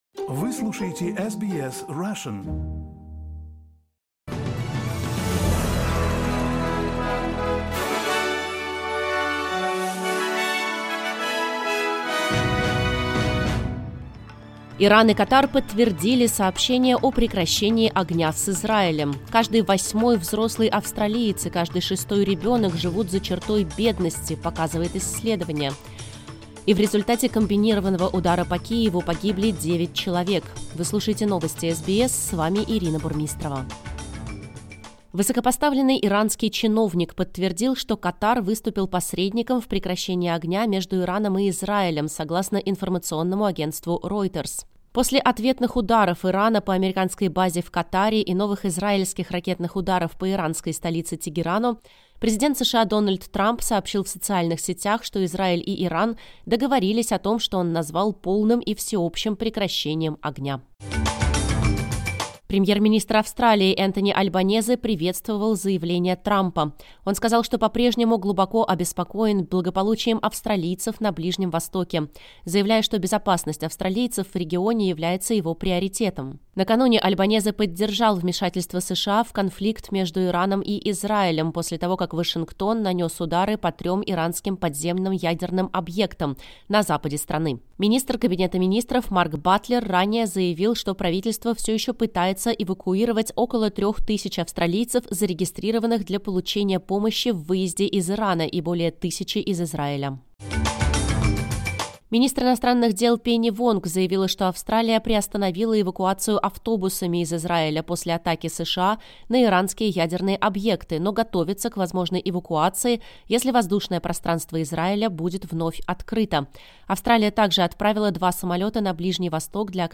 Новости SBS на русском языке — 24.06.2025